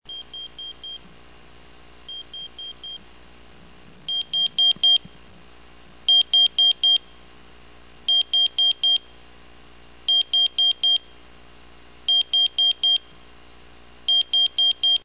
sonnerie